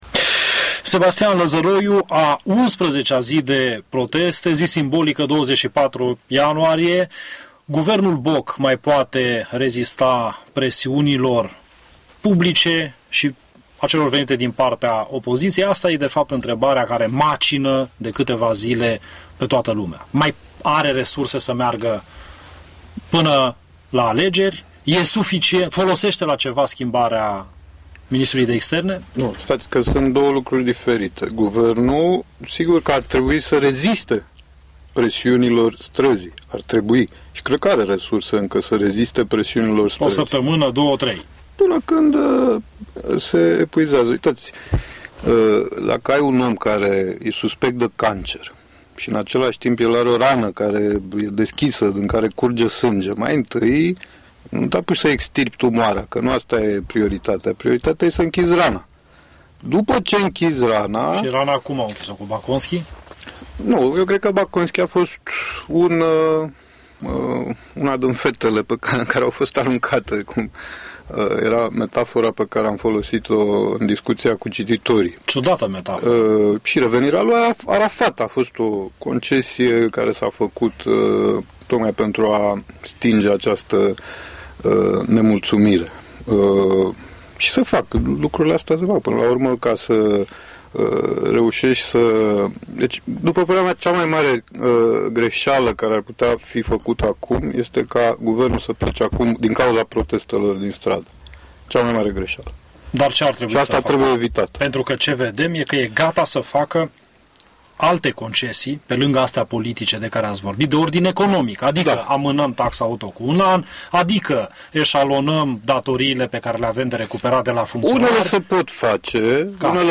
Asculta integral interviul cu Sebastian Lazaroiu